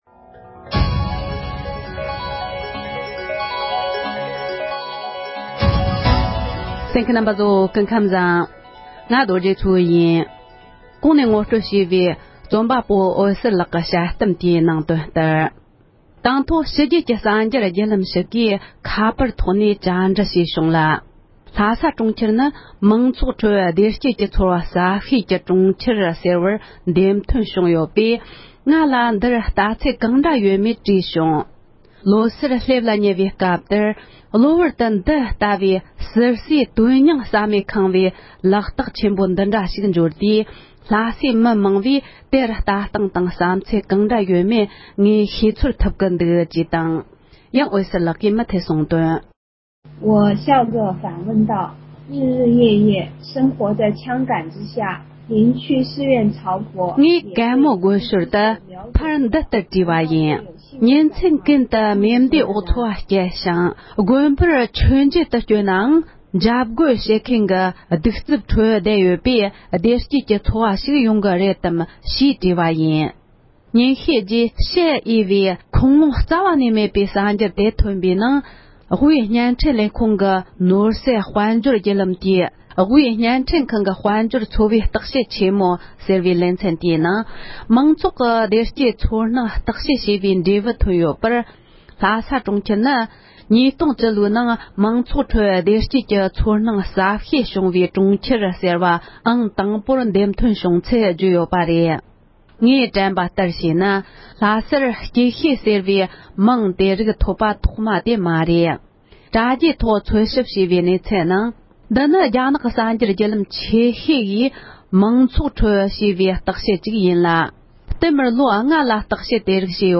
རྒྱ་ནག་དབུས་བརྙན་འཕྲིན་ཁང་གིས་ལྷ་སའི་མང་ཚོགས་ནི་རྒྱ་ནག་ནང་སྐྱིད་ཤོས་དེ་ཡིན་ཚུལ་བརྗོད་ཡོད་པ་ཞེས་པའི་དཔྱད་གཏམ།